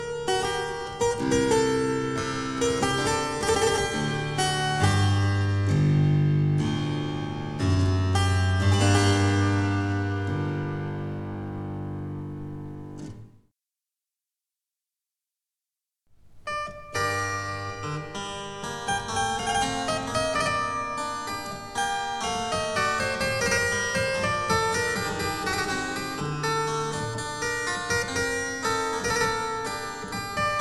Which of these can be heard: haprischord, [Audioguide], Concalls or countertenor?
haprischord